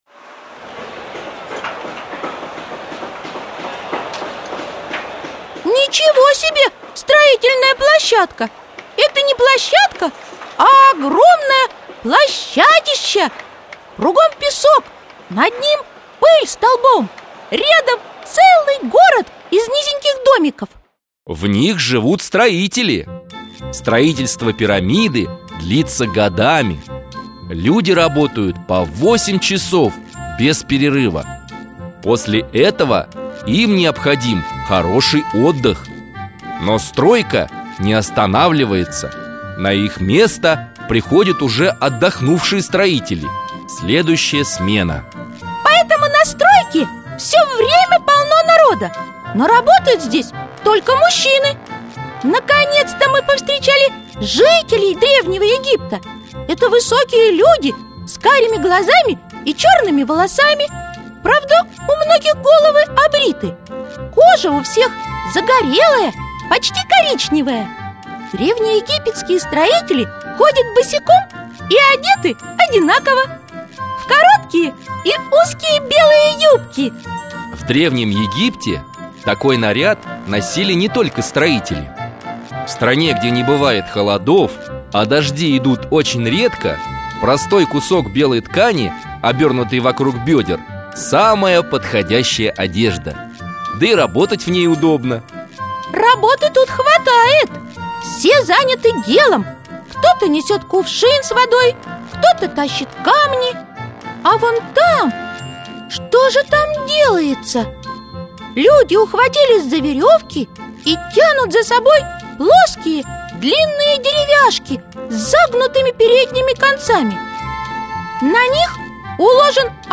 Аудиокнига Древний Египет | Библиотека аудиокниг
Прослушать и бесплатно скачать фрагмент аудиокниги